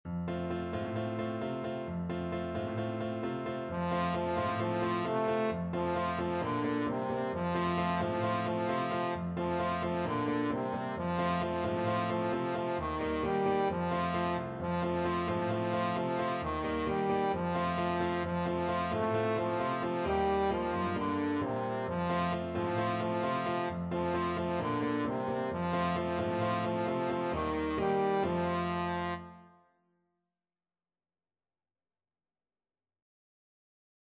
4/4 (View more 4/4 Music)
Fast =c.132
Caribbean Music for Trombone